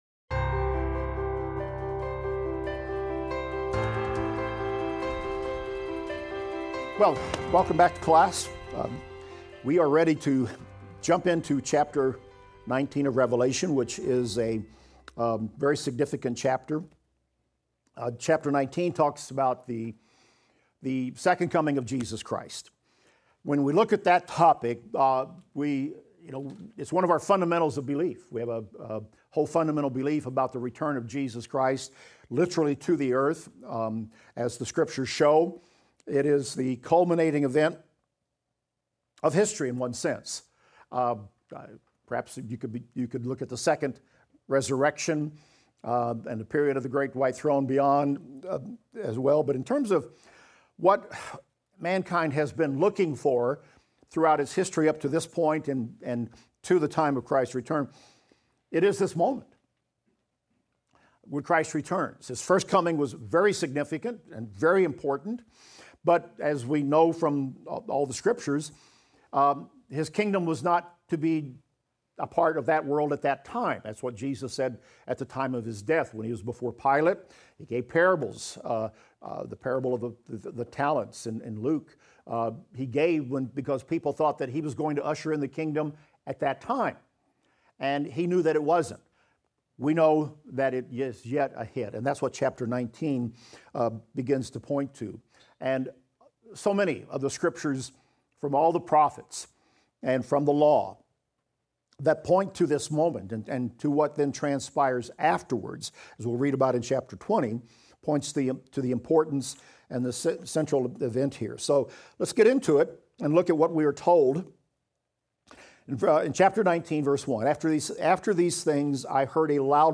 Revelation - Lecture 50 - 3audio.mp3